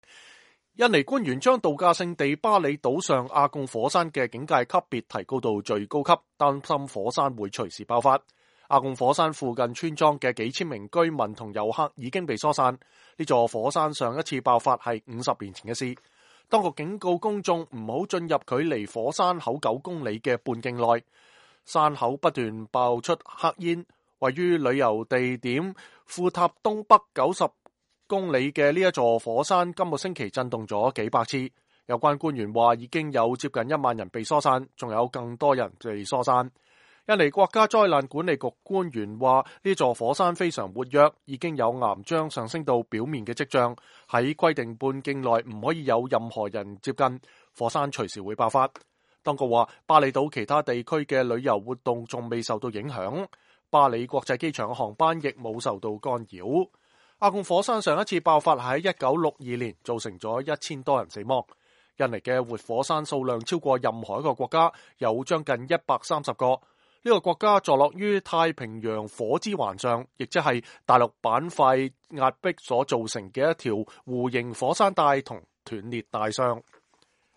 2017-09-23 美國之音視頻新聞: 巴厘阿貢火山會隨時爆發 (粵語)